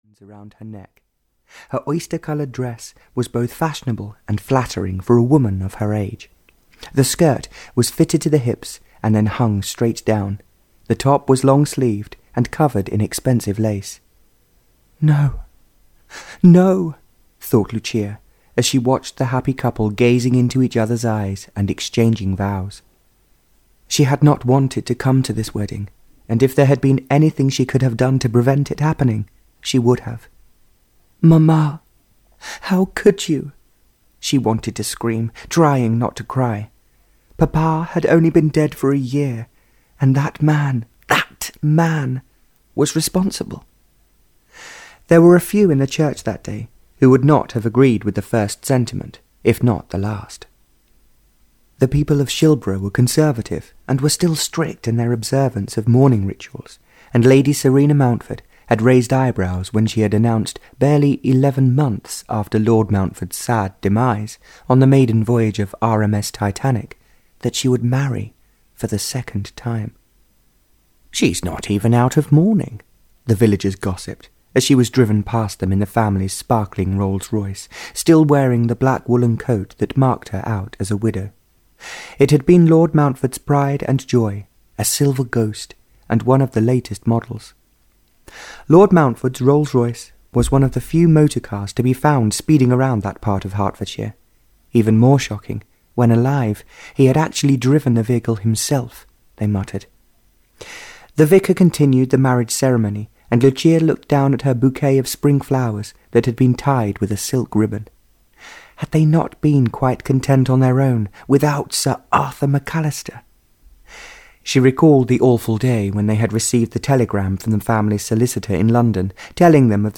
A Dream Come True (Barbara Cartland’s Pink Collection 40) (EN) audiokniha
Ukázka z knihy